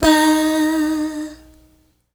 Note 2-D#.wav